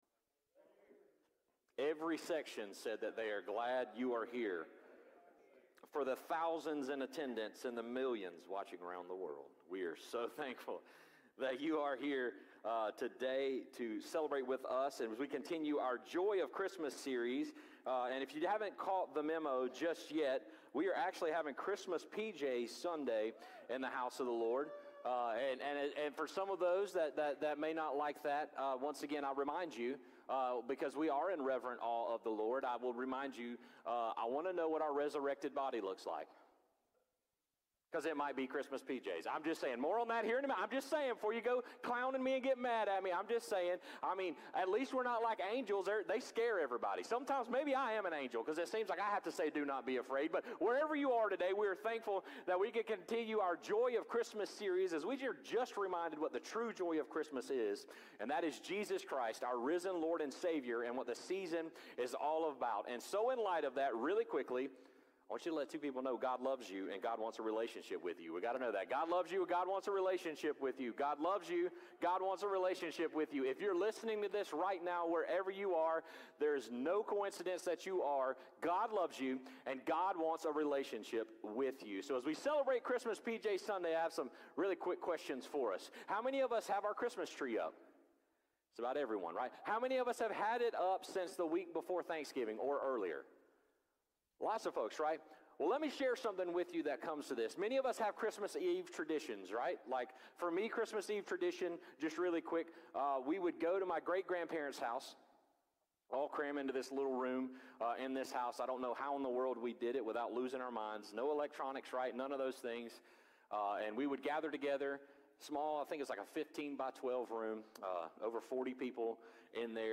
Sermons | The Vine Church